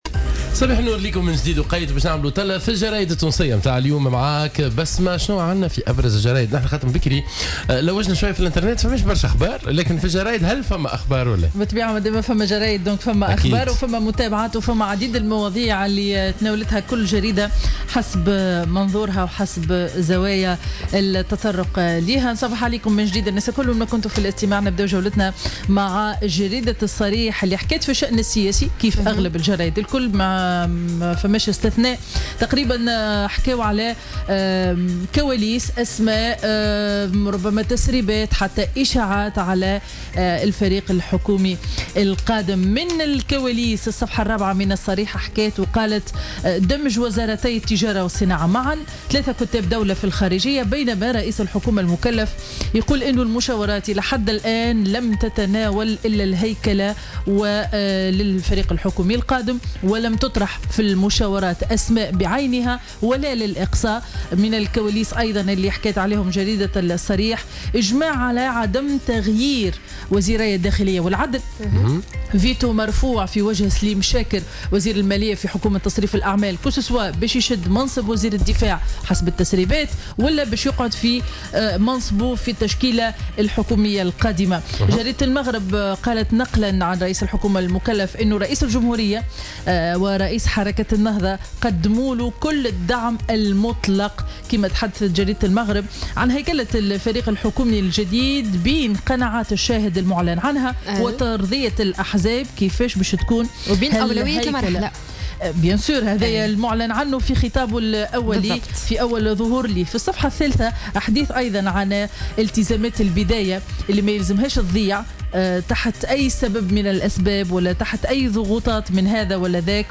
Revue de presse du Dimanche 7 Août 2016